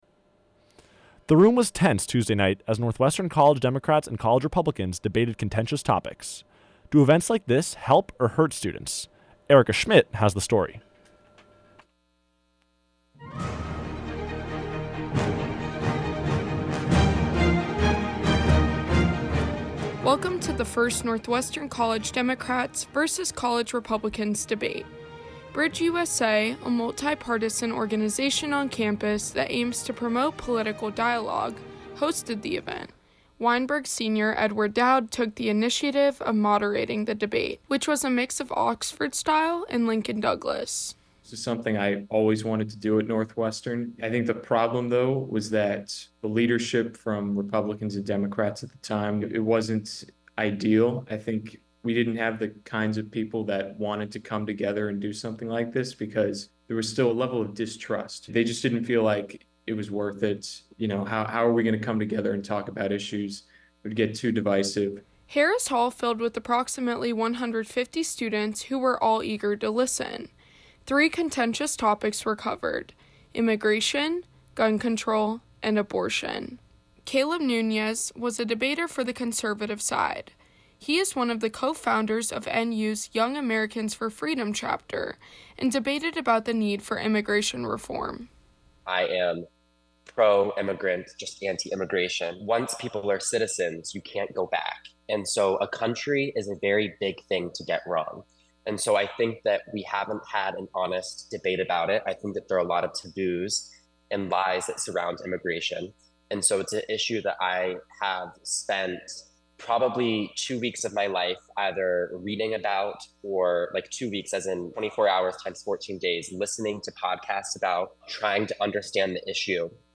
April 26, 2024: The NU Bridge debate, birding, the weather, and the latest Concert Countdown, and an update on the encampment at Deering Meadow. WNUR News broadcasts live at 6 pm CST on Mondays, Wednesdays, and Fridays on WNUR 89.3 FM.